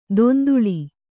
♪ donduḷi